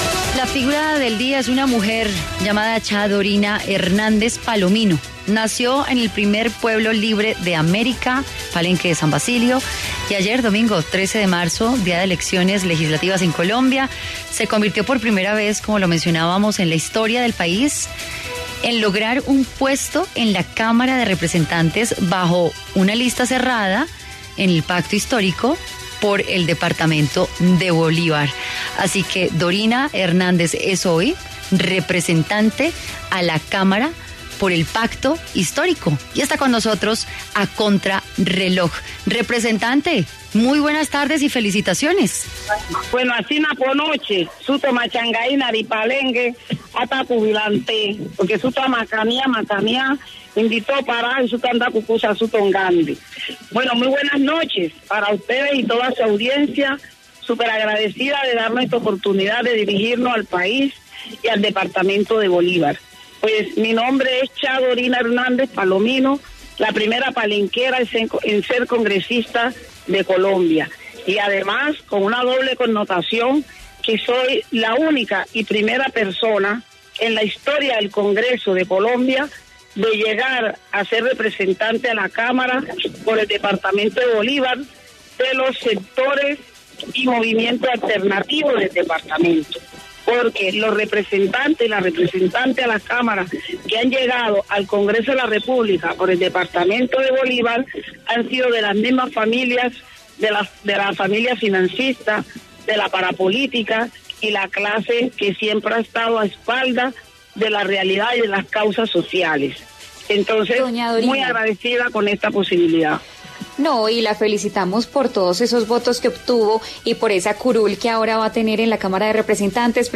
La palenquera Dorina Hernández Palomino, ganó un puesto en el Congreso de Colombia y habló para Contrarreloj acerca de sus propuestas.